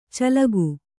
♪ calagu